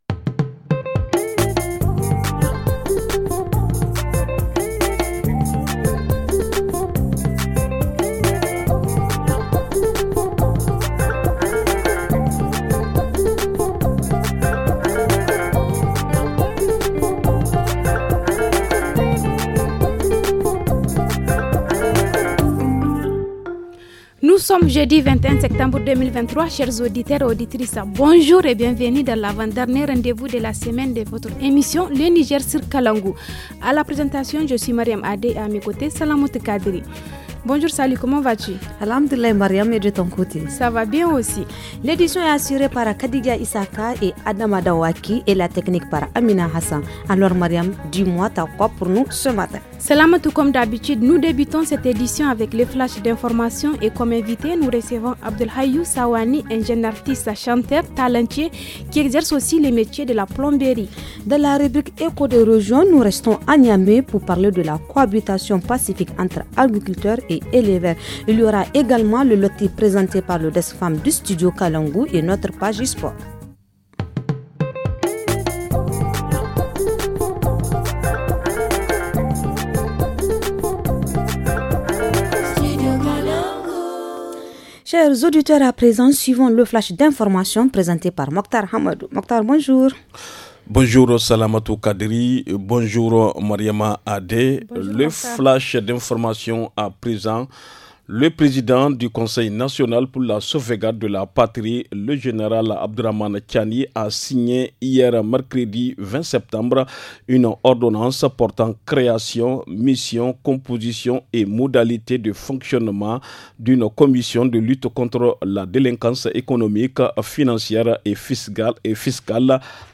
Playlist musique